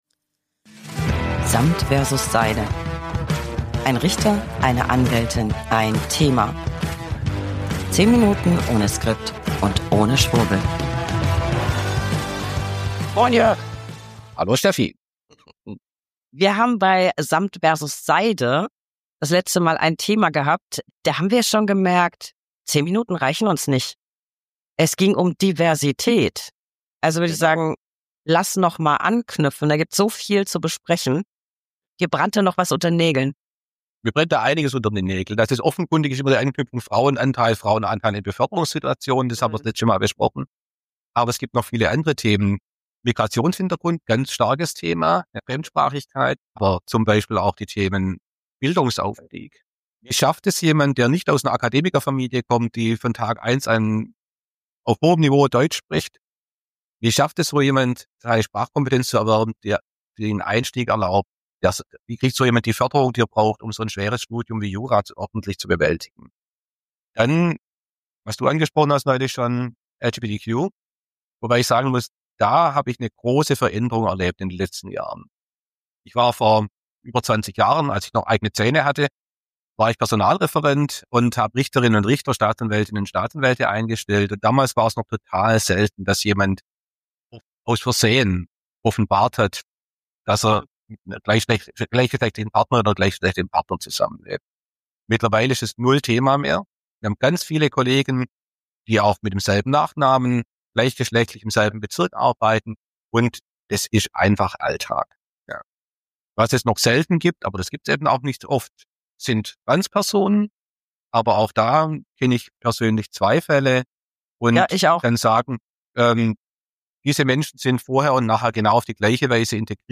Beschreibung vor 1 Jahr 1 Anwältin + 1 Richter + 1 Thema. 10 Minuten ohne Skript und ohne Schwurbel.